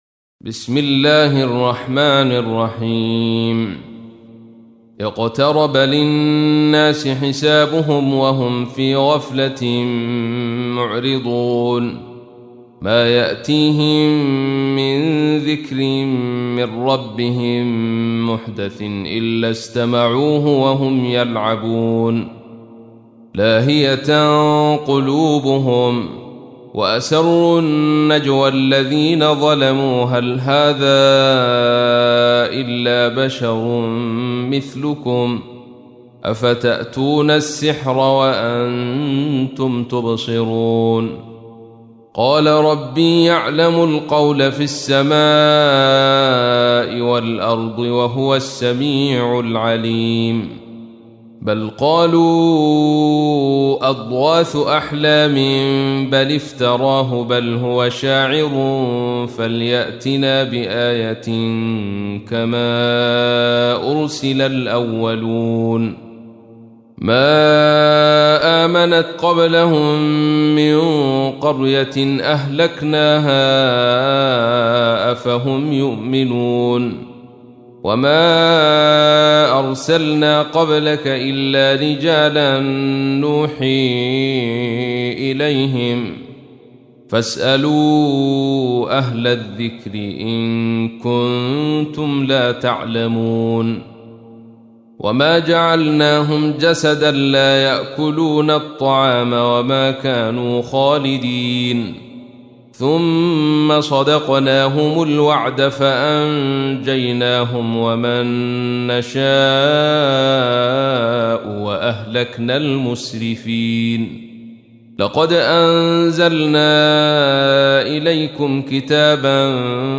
تحميل : 21. سورة الأنبياء / القارئ عبد الرشيد صوفي / القرآن الكريم / موقع يا حسين